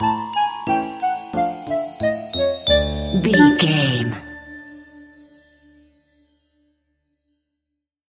Uplifting
Aeolian/Minor
Slow
flute
oboe
piano
percussion
silly
goofy
comical
cheerful
perky
Light hearted
quirky